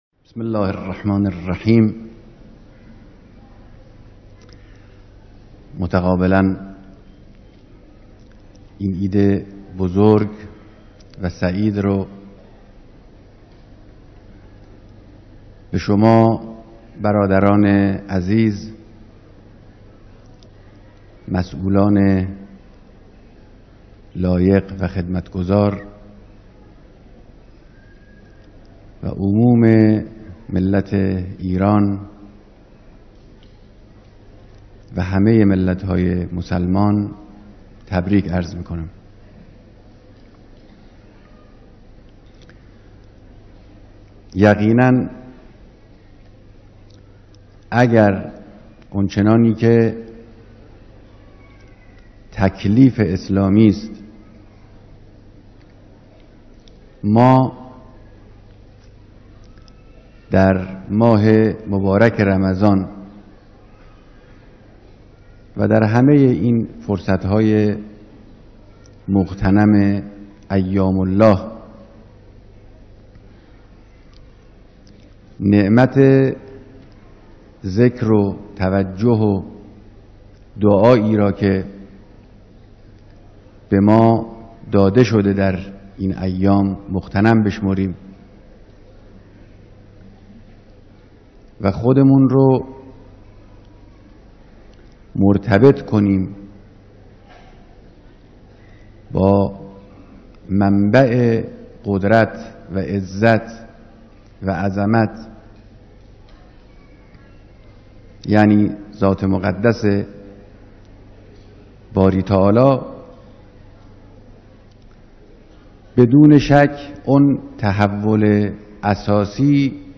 بیانات در دیدار مسؤولان و کارگزاران نظام، به مناسبت عید سعید فطر